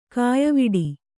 ♪ kāyaviḍi